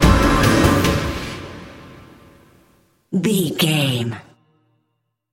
Thriller
Aeolian/Minor
strings
drums
cello
violin
percussion
tension
ominous
dark
suspense
haunting
creepy